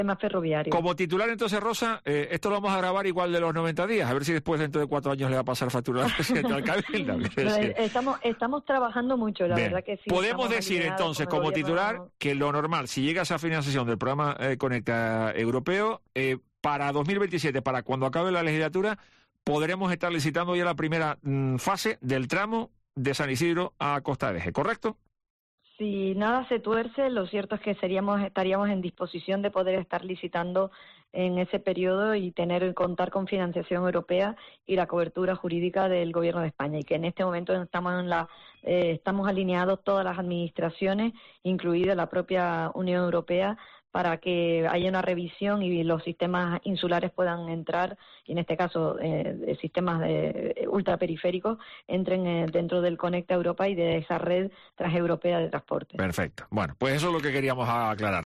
Son palabras de la presidenta del Cabildo tinerfeño, Rosa Dávila, que hoy en Herrera en COPE Tenerife, ha dejado por sentado que “el proyecto está muy avanzado”, y la apuesta decidida de la primera institución insular por esta infraestructura clave de comunicación, que estaría licitada en 2027.